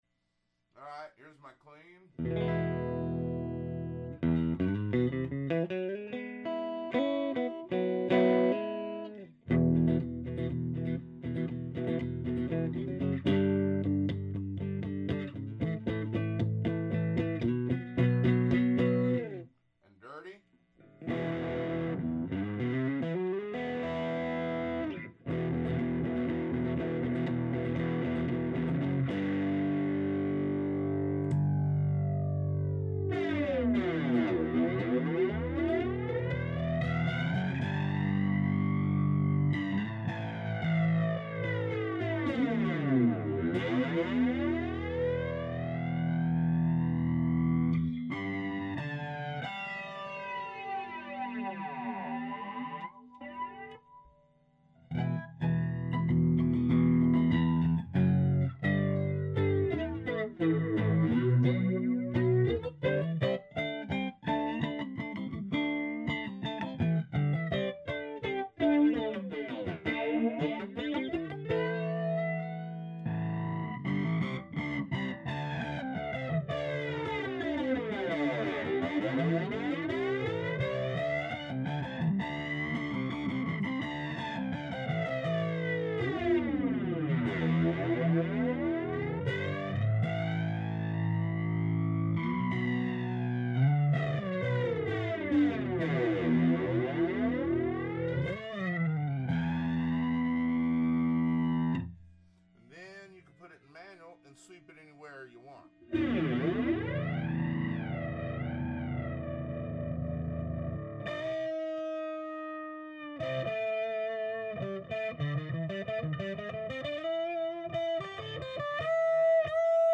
Here's a clip of that Ross Flanger ..... I ran a Tube Works Real Tube pedal into the Ross and ran that into my Ampeg Reverbrocket .... set the Teac DR-05 5" off the floor about 3 feet in front of the speaker.
Or you can choose automatic sweep and there's a mix knob that lets you vary from fully manual to fully auto or anywhere in between so you can use manual but add just a little sweep for instance.
I ran the intensity pretty high to make it easy to hear what it's doing. I think you'd usually use less flange than I have on this clip but I have it cranked to about 3/4s most of this clip.